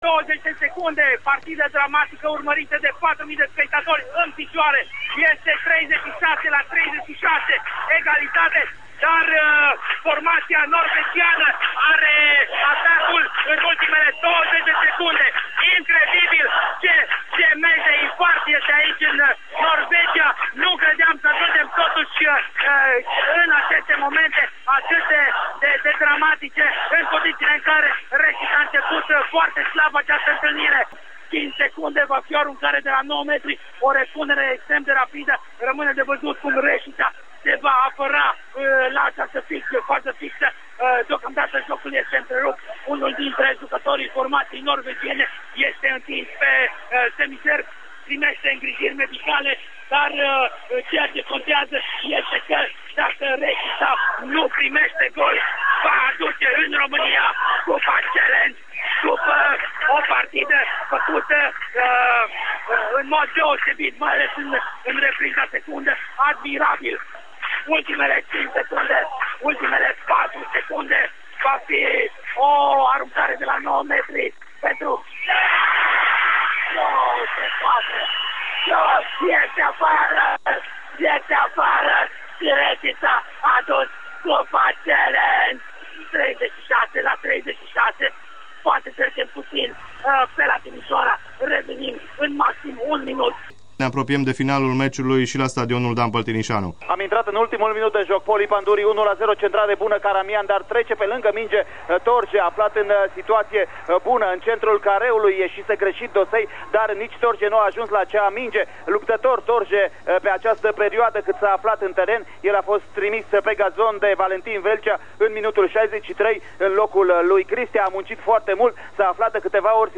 Poate vă mai amintiți de aceste generice, de aceste semnale.
Sâmbătă, 11-13 a devenit un reper pentru iubitorii fotbalului din regiune, pe acest tronson fiind difuzate aspecte în direct de la meciurile etapelor de Divizia B. Aș spune că principala caracteristică a Diviziei Sport de-a lungul celor 33 de ani de existență a fost reprezentată de transmisiuni.